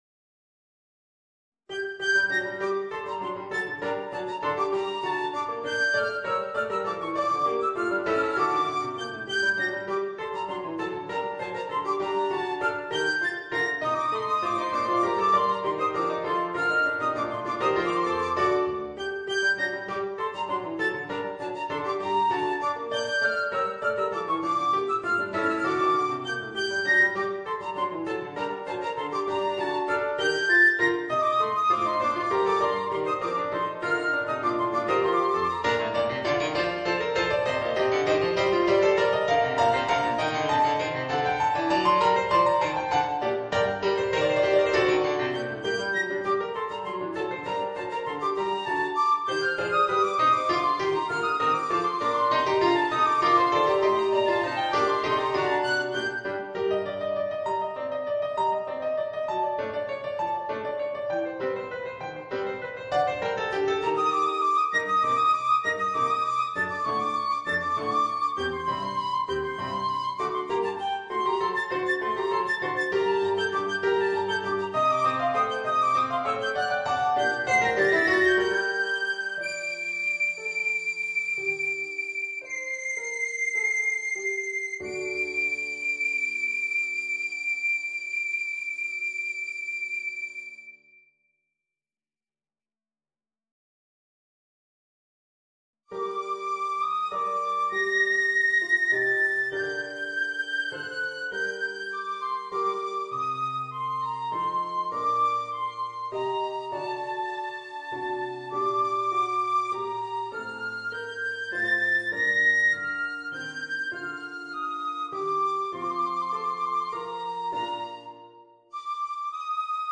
Voicing: Piccolo and Piano